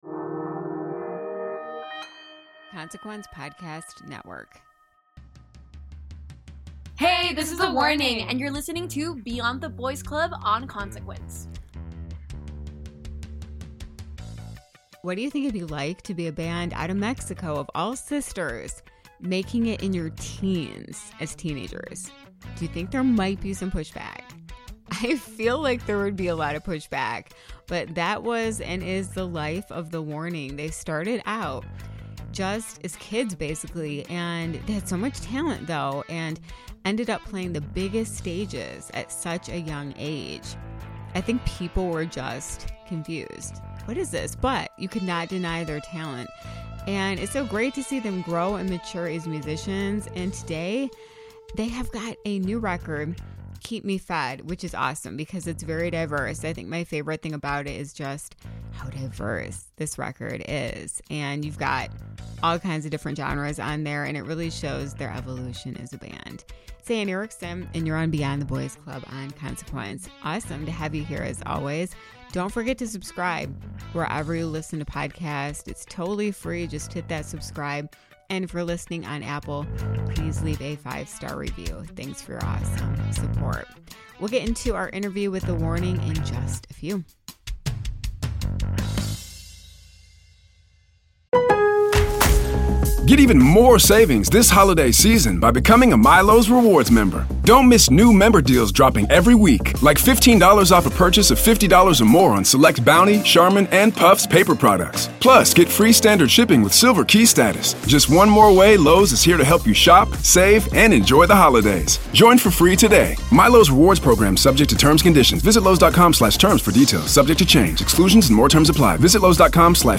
On this episode of Beyond The Boys Club, listen to The Warning talk abou their journey.